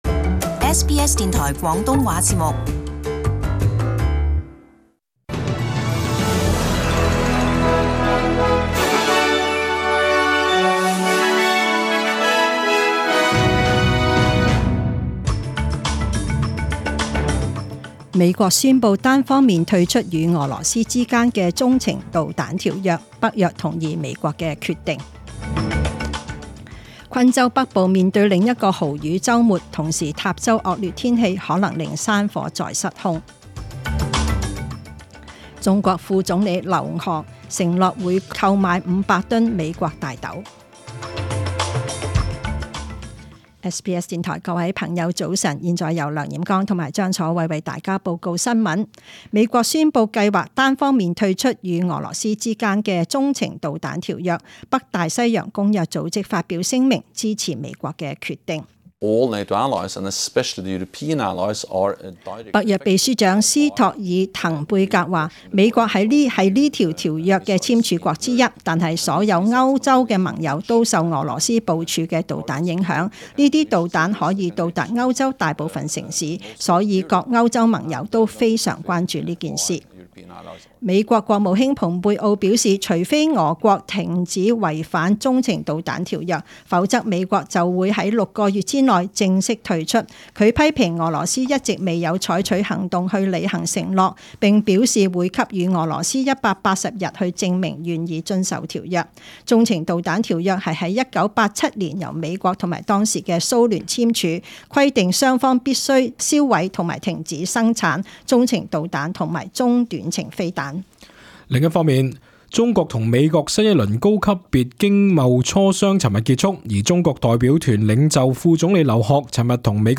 Detailed morning news bulletin